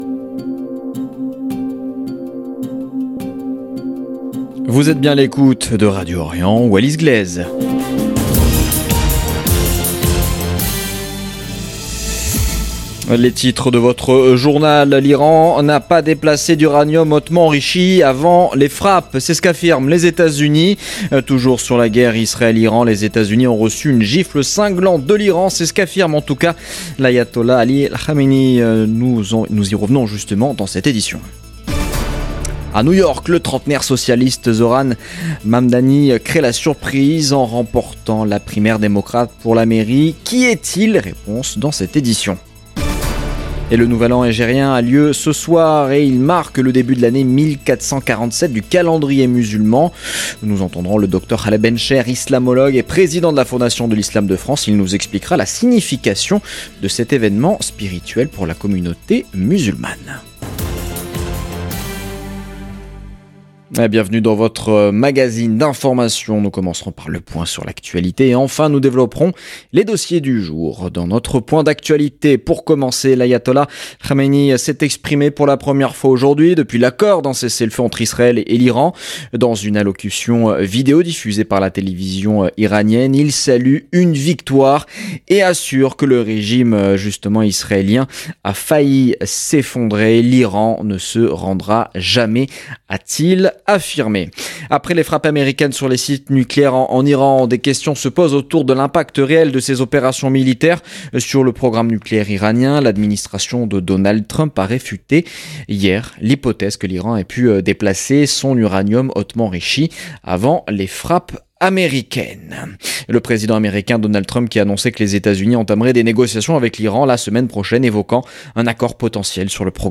Magazine de l'information de 17H00 du 26 juin 2025